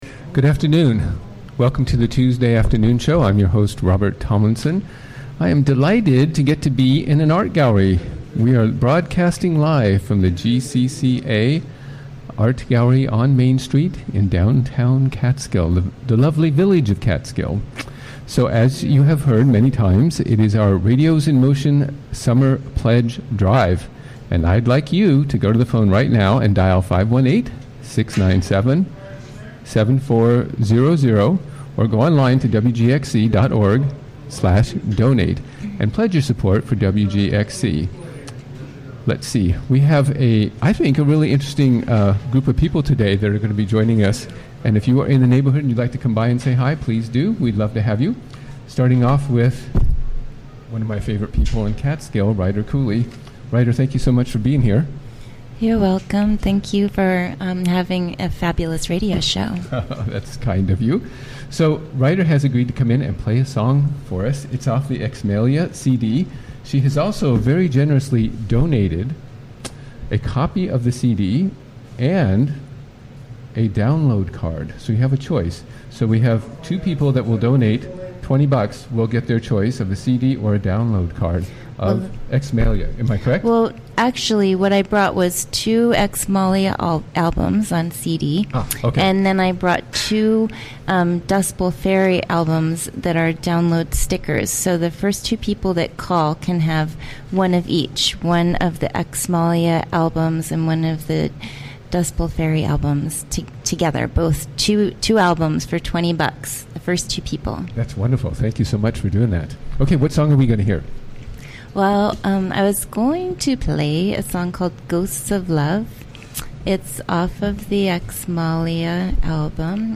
Recorded during the WGXC Afternoon Show of Tuesday, June 13, 2017.